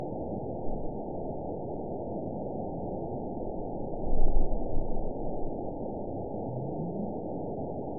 event 912352 date 03/25/22 time 06:38:18 GMT (3 years, 1 month ago) score 9.48 location TSS-AB01 detected by nrw target species NRW annotations +NRW Spectrogram: Frequency (kHz) vs. Time (s) audio not available .wav